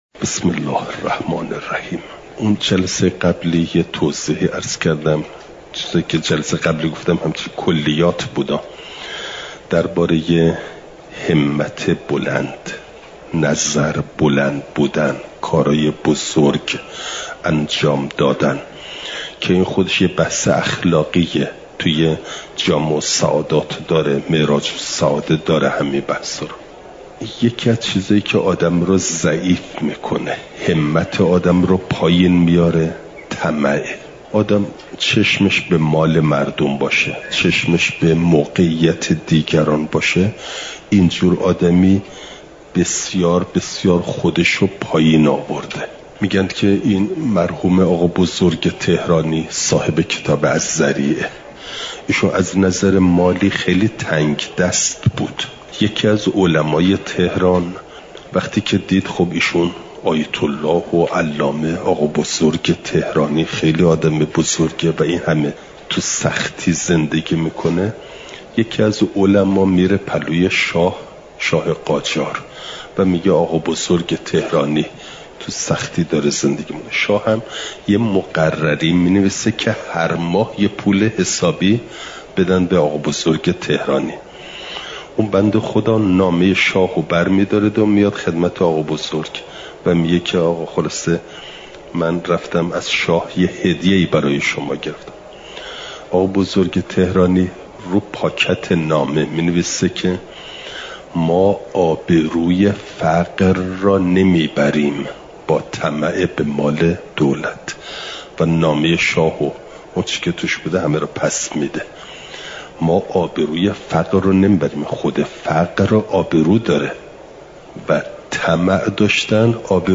چهارشنبه ۷ آبانماه ۱۴۰۴، حرم مطهر حضرت معصومه سلام ﷲ علیها